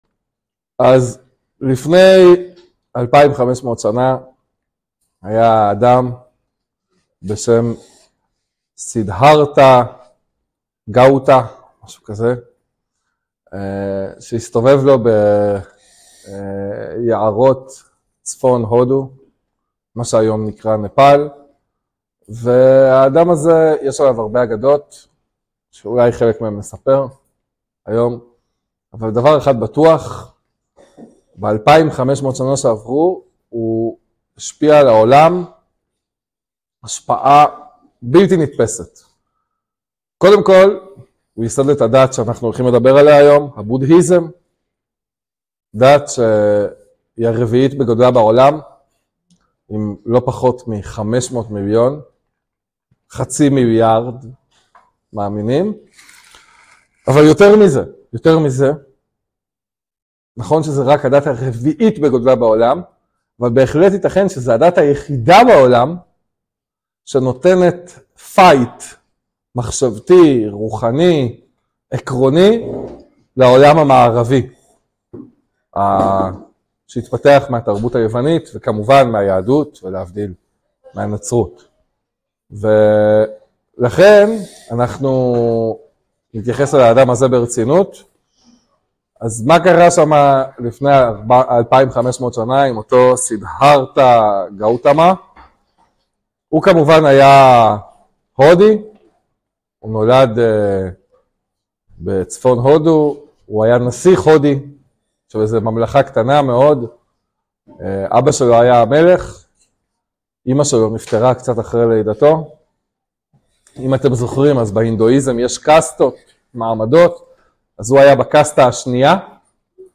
שיעור שני על דתות הדהרמה - בודהיזם